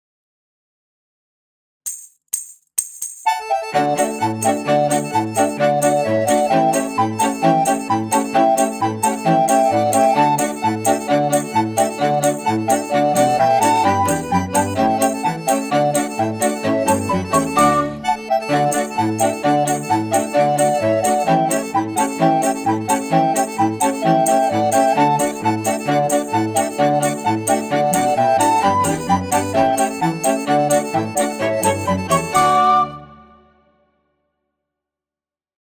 Gattung: für Querflöte und Klavier
Besetzung: Instrumentalnoten für Flöte